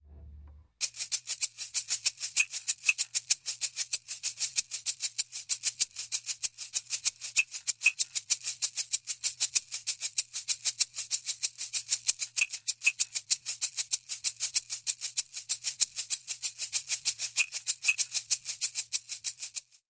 Звук который нравится кошкам шейкер